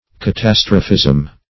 Meaning of catastrophism. catastrophism synonyms, pronunciation, spelling and more from Free Dictionary.
Search Result for " catastrophism" : The Collaborative International Dictionary of English v.0.48: Catastrophism \Ca*tas"tro*phism\, n. (Geol.)